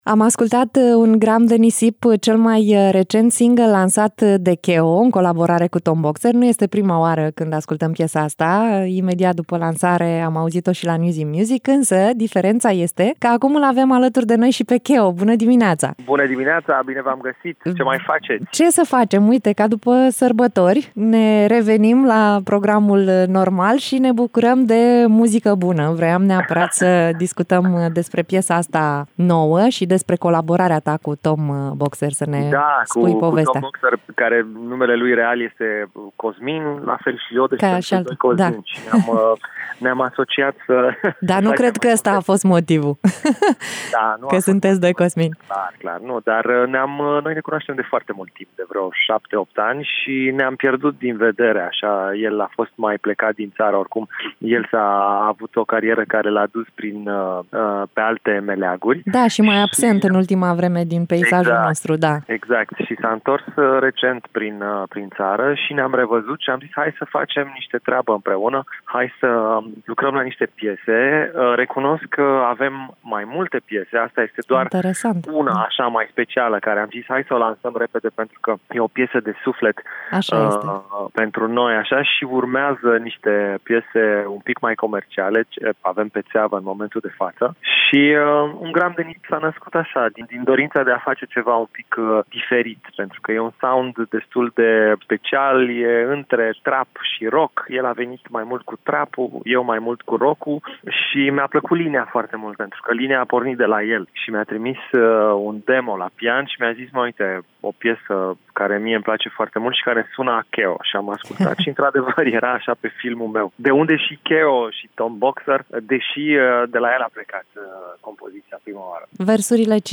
05-Mai-Interviu-KEO.mp3